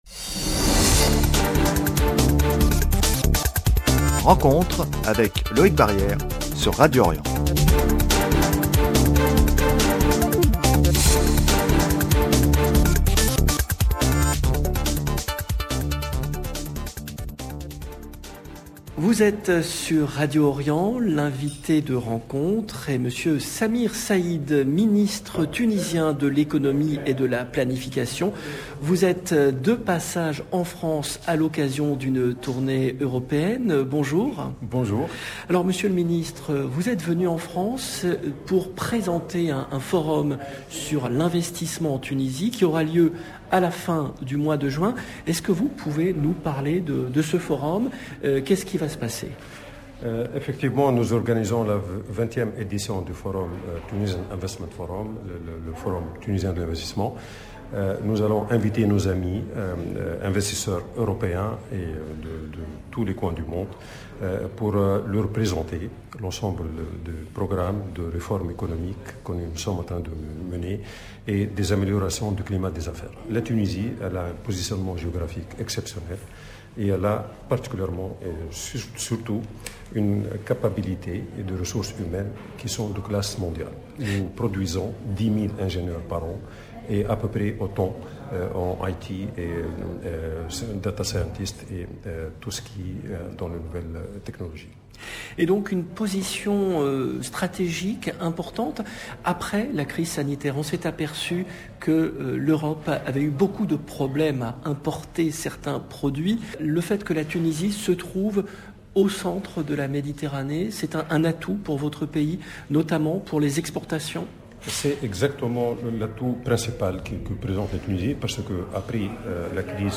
Radio Orient reçoit Samir Saïd , le ministre tunisien de l’Economie et de la planification qui effectue une tournée européenne dans le cadre de la préparation du forum sur l’investissement en Tunisie, prévu le mois prochain.